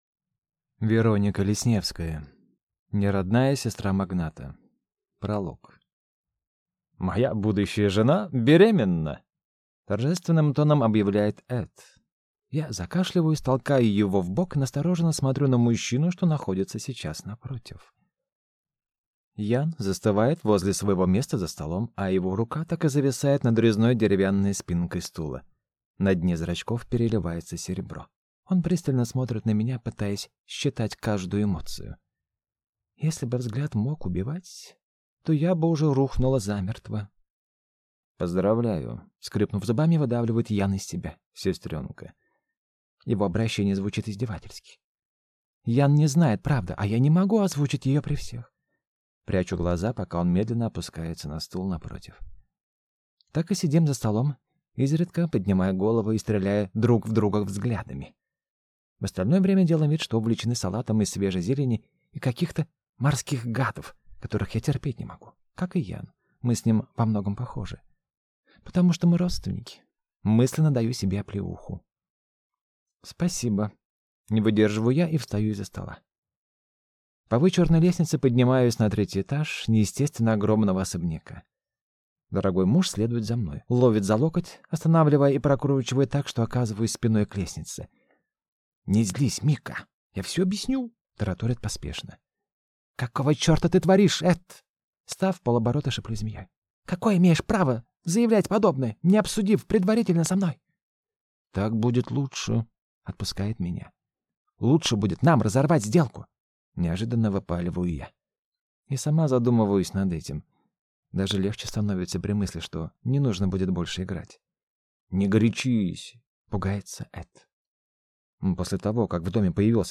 Аудиокнига НеРодная сестра магната | Библиотека аудиокниг